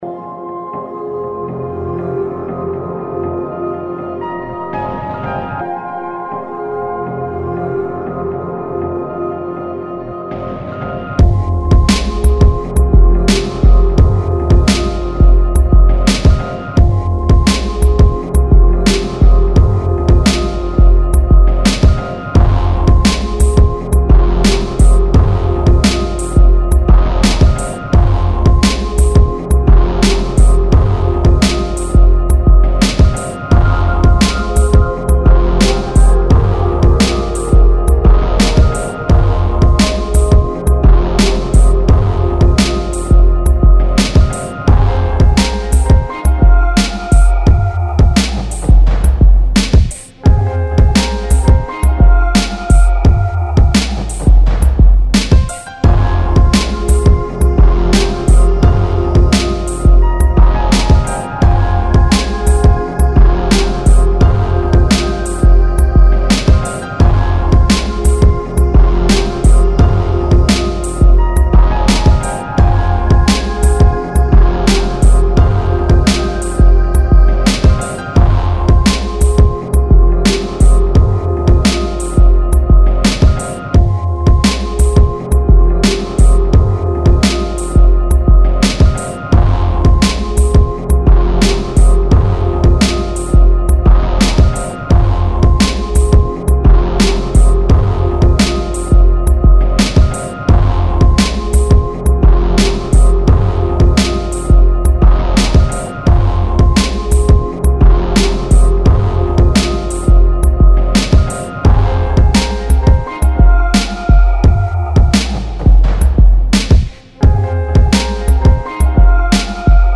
115 BPM.